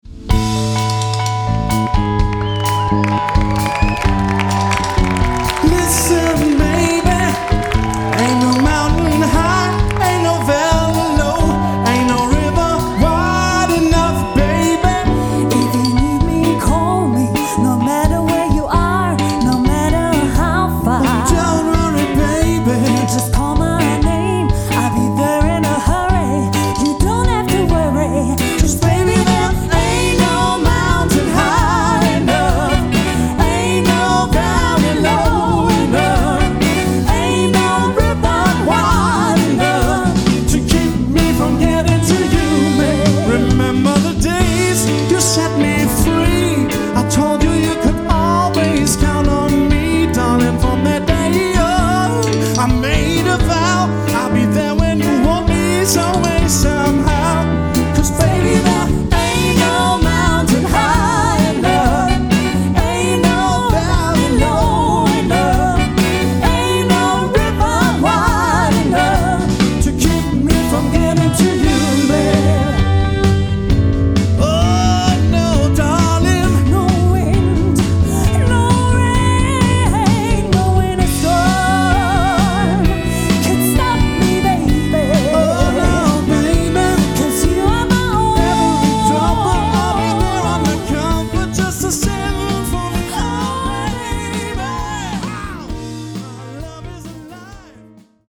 as recorded live in Braunschweig